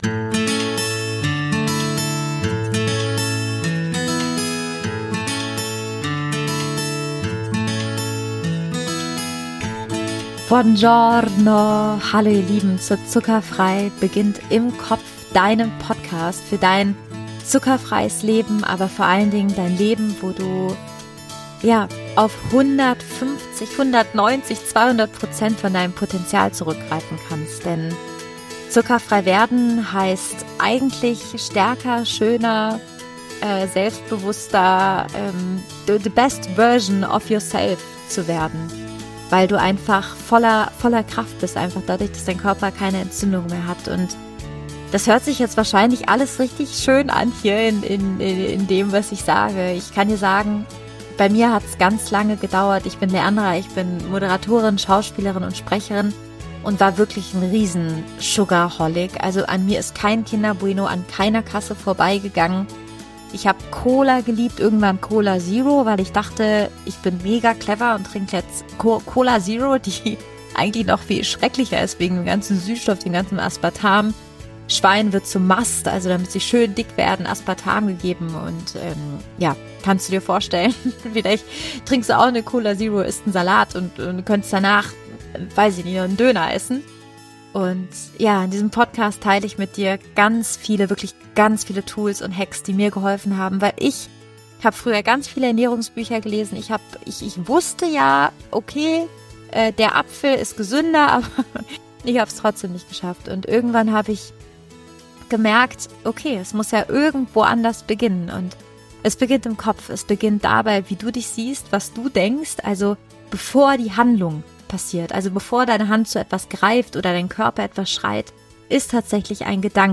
Mein wunderbares Ich: Wie Gene, Ernährung und Zucker unser Selbstbild formen- Interview